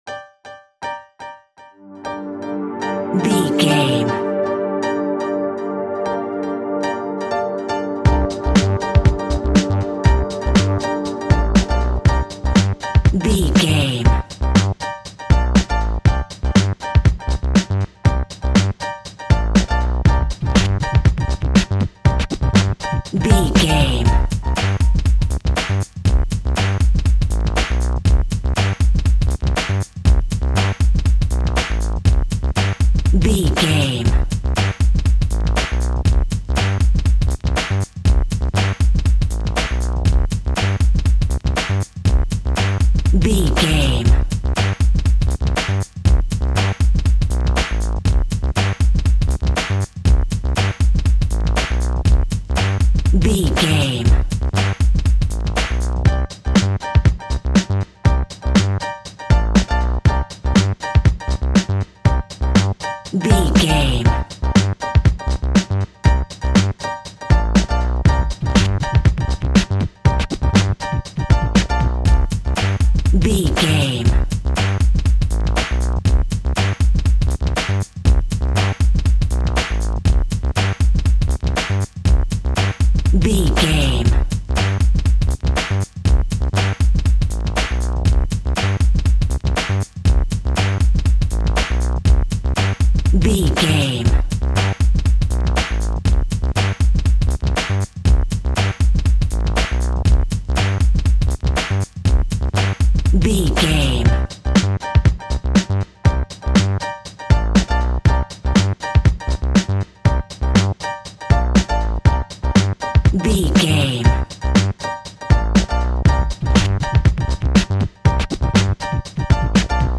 West Coast Hip Hop Music Cue.
Aeolian/Minor
Funk
electronic
drum machine
synths